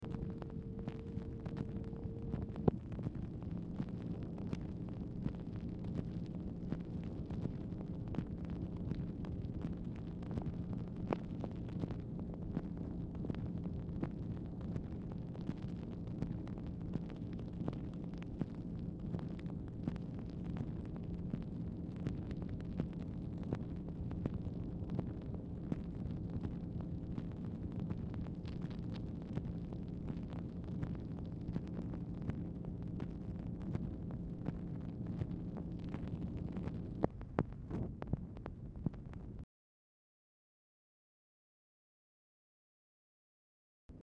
Telephone conversation # 4644, sound recording, MACHINE NOISE, 8/3/1964, time unknown | Discover LBJ
Format Dictation belt
Specific Item Type Telephone conversation